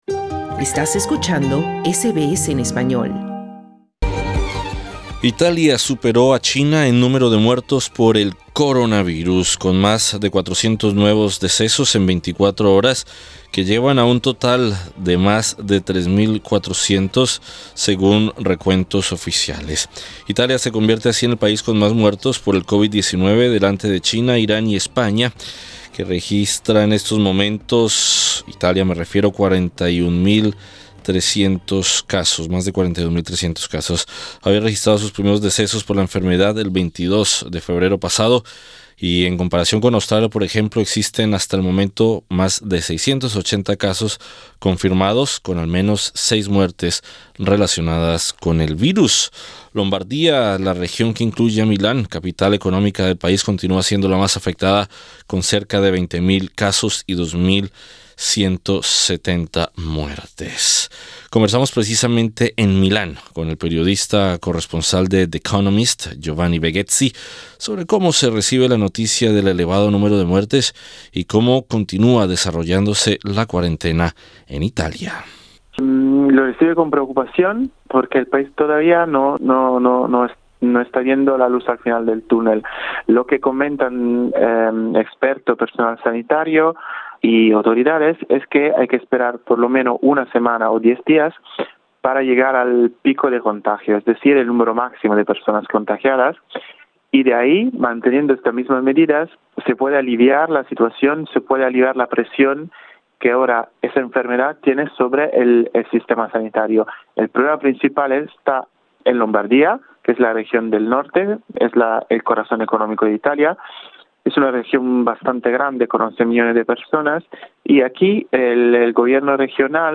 Conversamos en Milán con el periodista